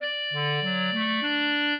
clarinet
minuet15-4.wav